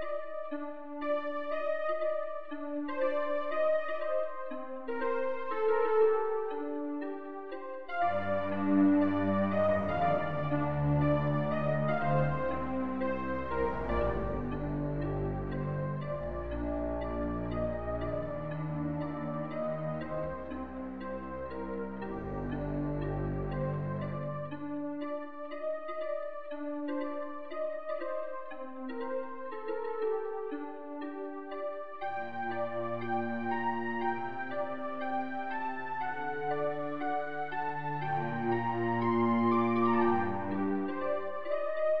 theme-loop.ogg